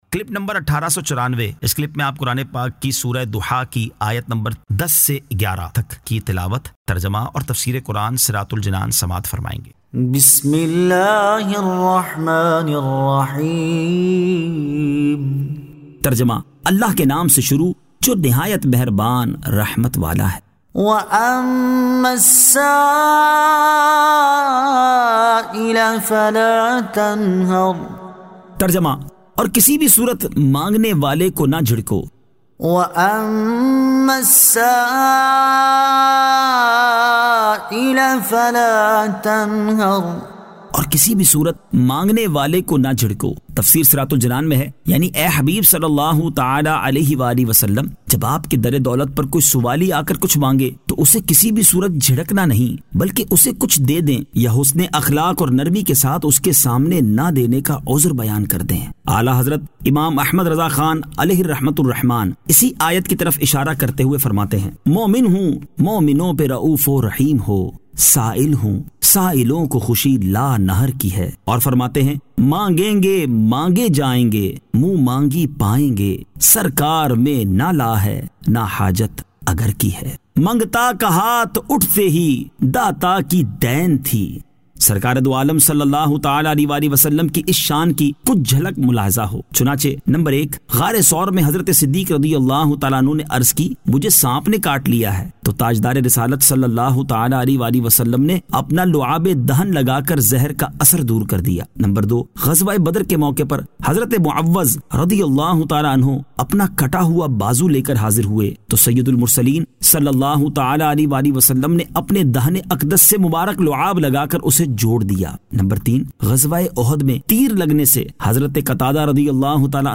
Surah Ad-Duhaa 10 To 11 Tilawat , Tarjama , Tafseer